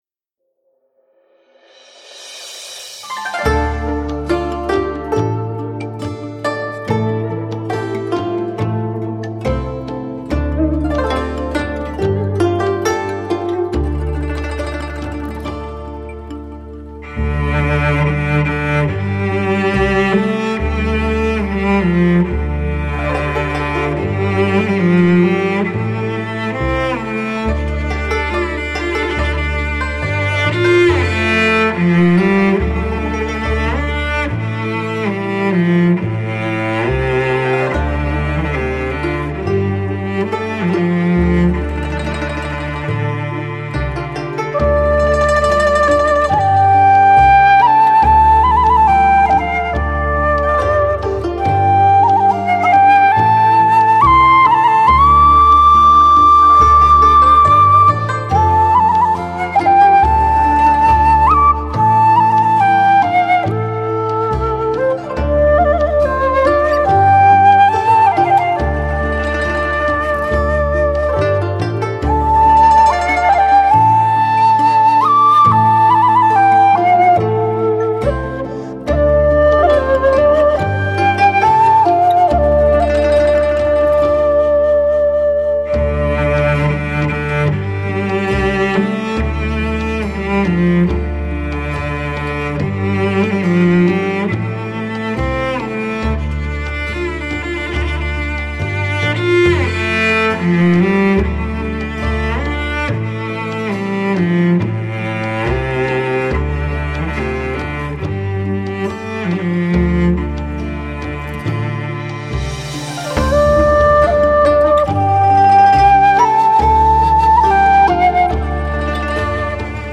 跨越半个世纪的红歌爱恋 全新录音珍贵纪念发烧版
极致悠扬的民族乐器演绎 从经典感受非凡红色岁月